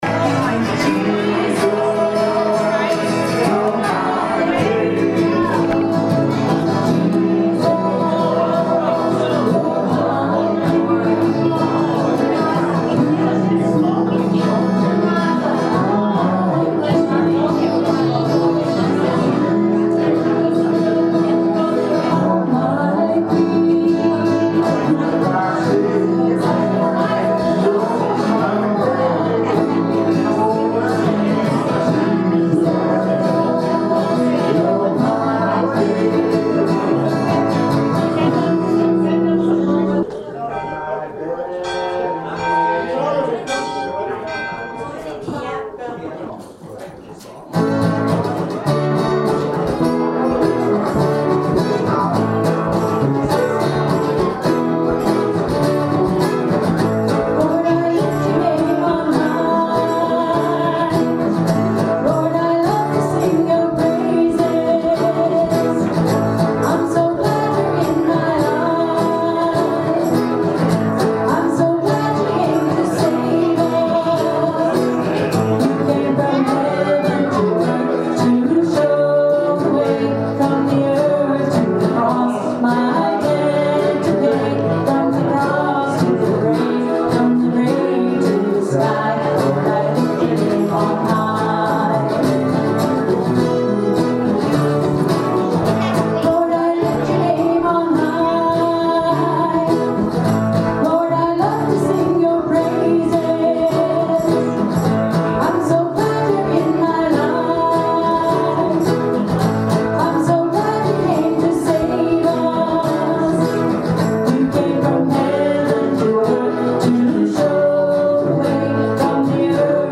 December 4th, 2016 Service + Communion Podcast
Welcome to the December 4th, 2016 Service + Communion Podcast.